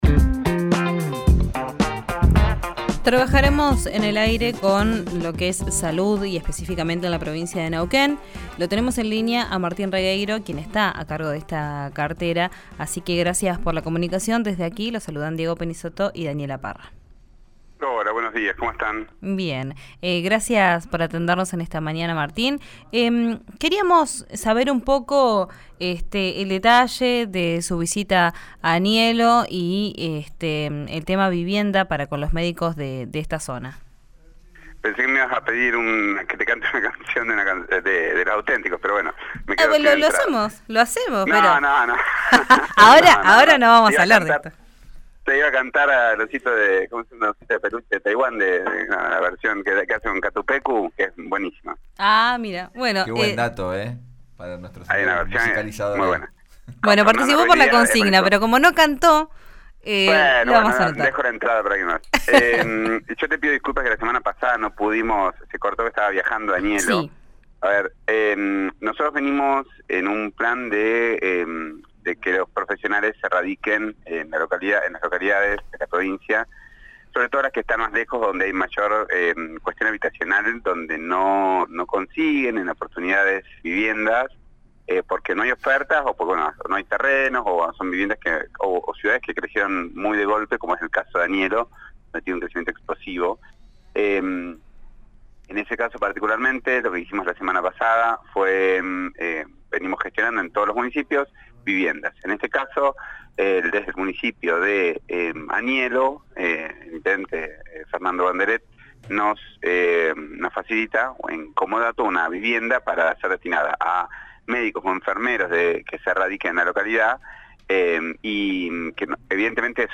Escuchá a Martín Regueiro, ministro de Salud de Neuquén, en RÍO NEGRO RADIO: